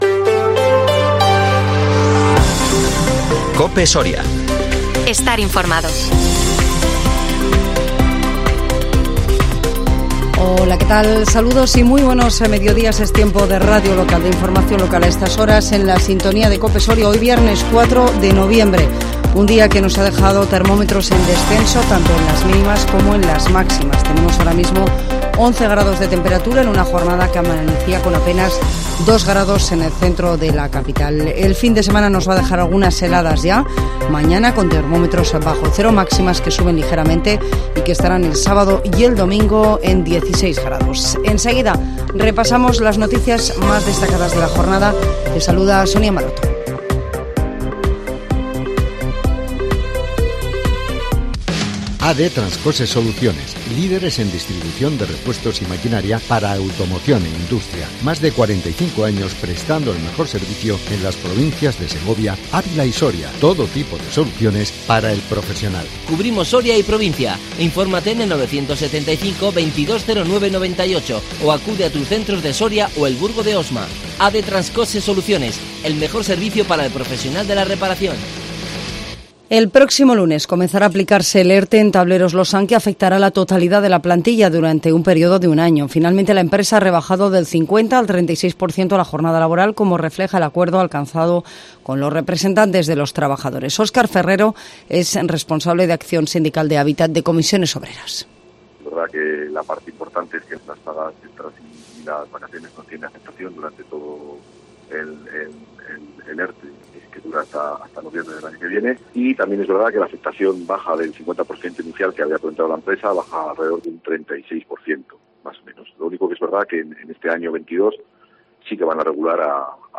INFORMATIVO MEDIODÍA COPE SORIA 4 NOVIEMBRE 2022